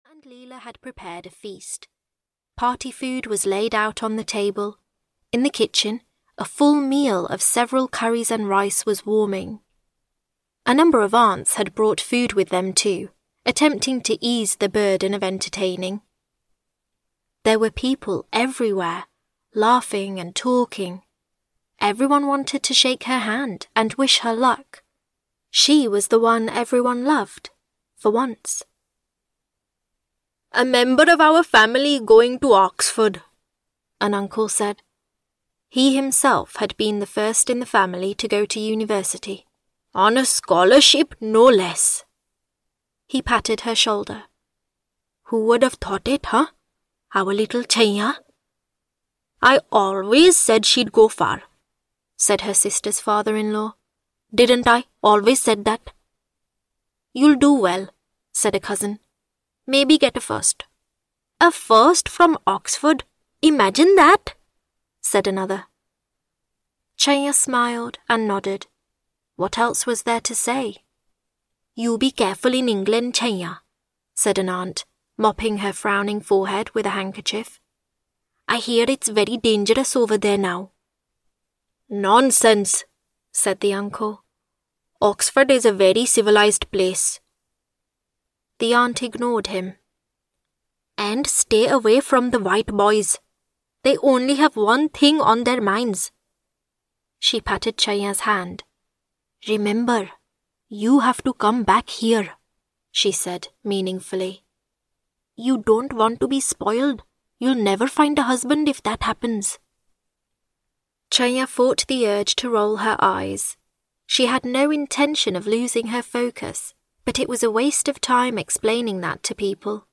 A Convenient Marriage (EN) audiokniha
Ukázka z knihy